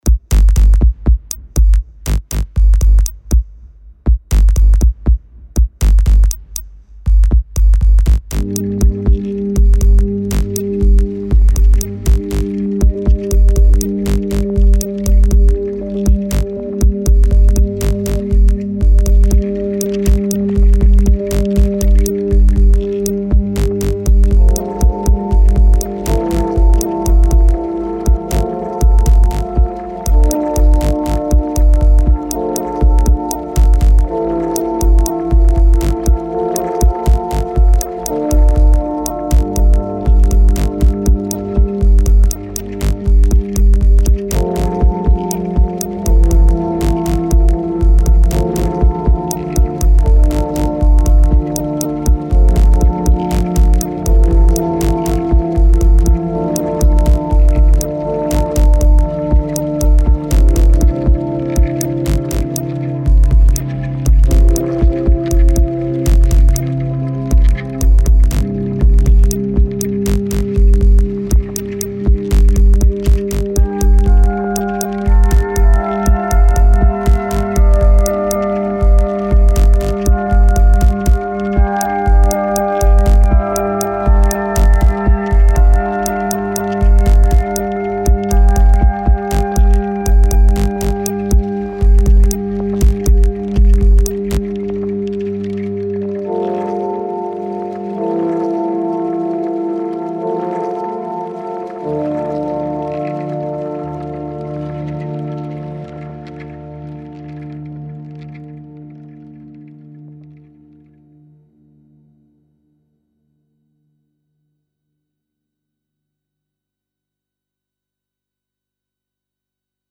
Downtempo